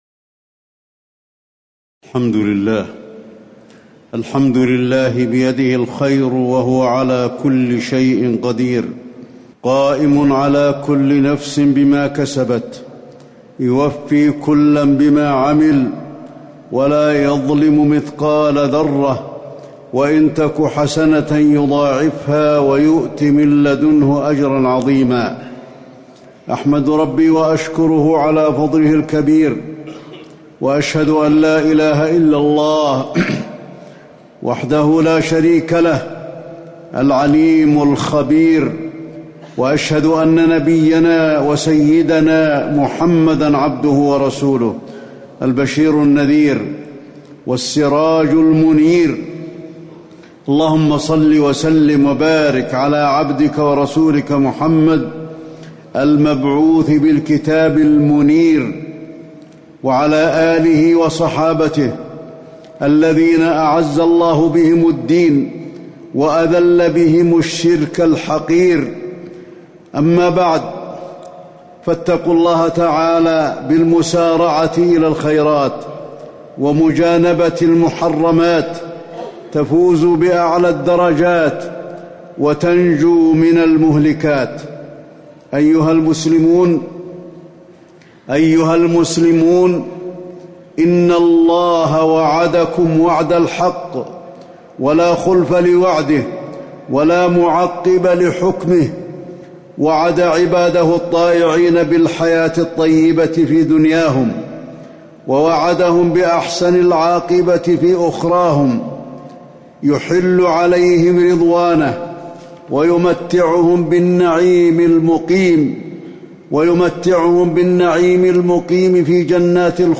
تاريخ النشر ١١ شعبان ١٤٣٩ هـ المكان: المسجد النبوي الشيخ: فضيلة الشيخ د. علي بن عبدالرحمن الحذيفي فضيلة الشيخ د. علي بن عبدالرحمن الحذيفي مجاهدة النفس والحذر من كيد الشيطان